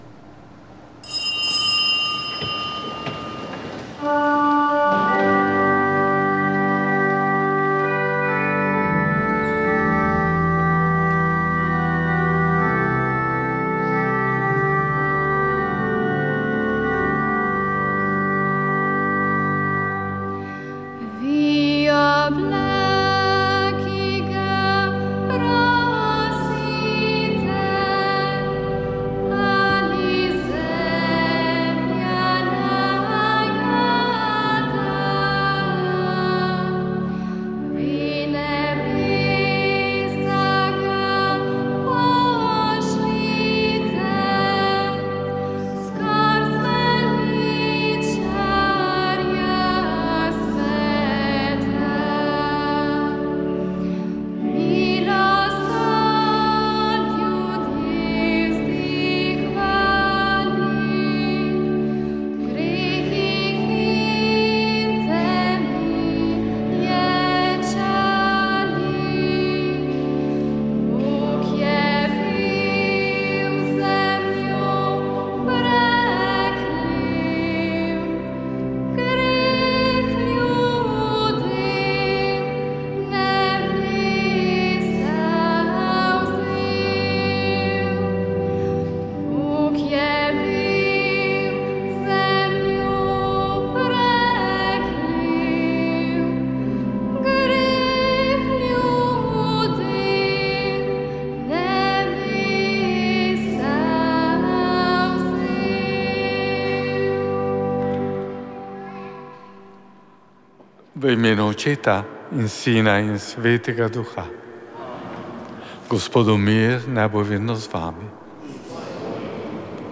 Sveta maša
Sv. maša iz cerkve Marijinega oznanjenja na Tromostovju v Ljubljani 5. 12.